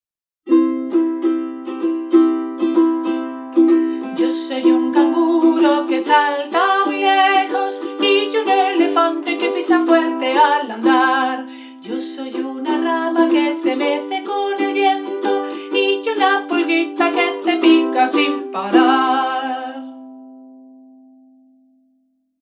Canción modo mayor: El canguro
Cancion-modo-mayor-el-canguro.mp3